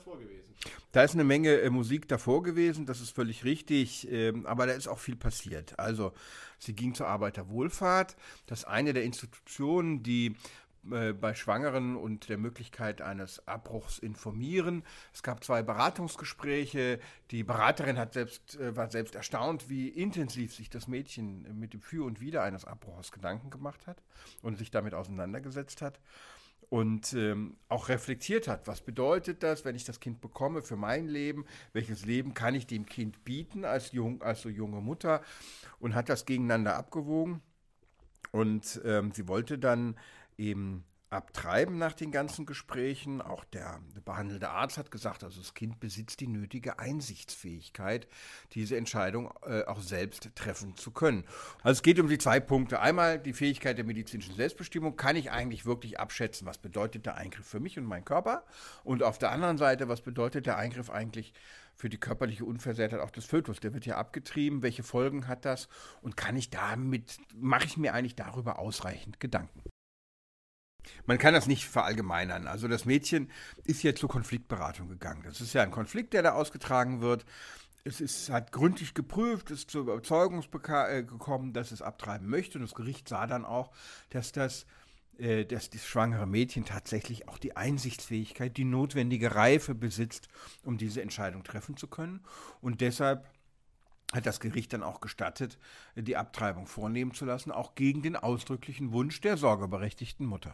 Kollegengespräch: Darf eine 16jährige ohne Zustimmung der Eltern abtreiben?